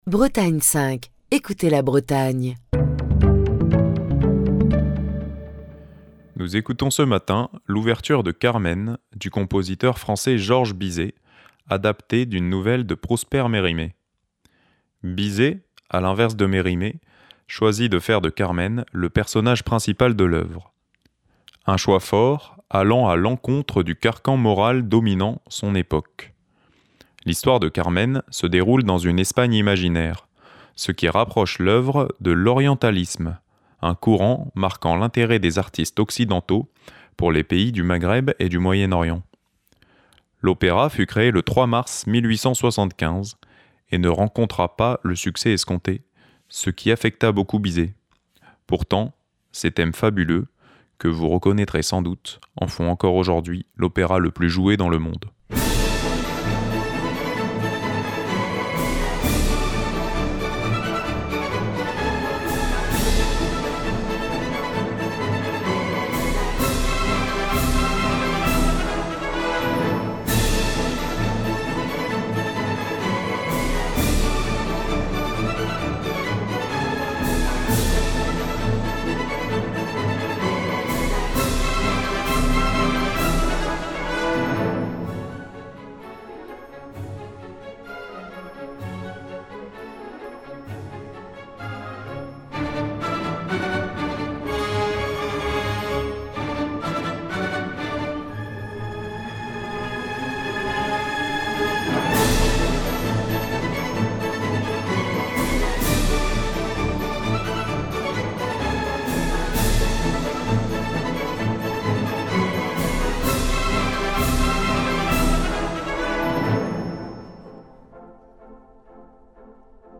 Voici donc l’ouverture de "Carmen", opéra-comique de Georges Bizet, interprétée par Michel Plasson et l’Orchestre du Capitole de Toulouse.